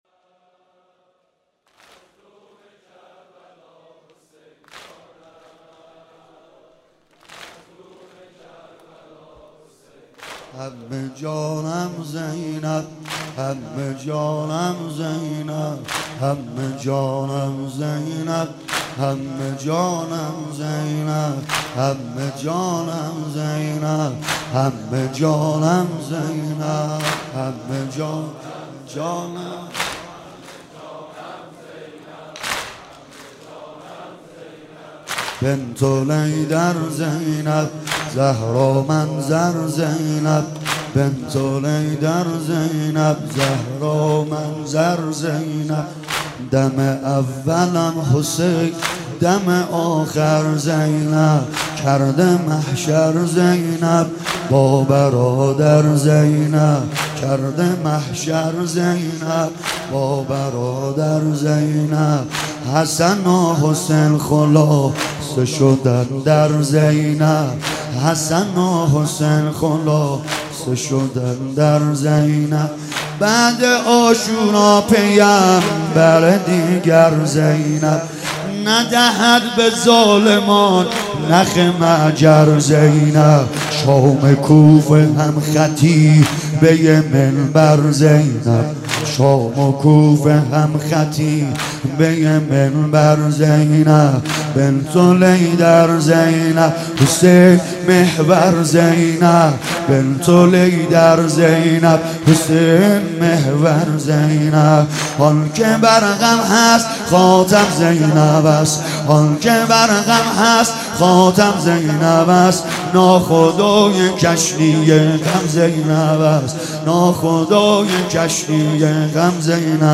مراسم عزاداری ظهر عاشورا محرم 95/هیئت انصار الحسین(ع)